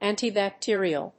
音節ànti・bactérial 発音記号・読み方
/ˌæntibæˈktɪriʌl(米国英語), ˌænti:bæˈktɪri:ʌl(英国英語)/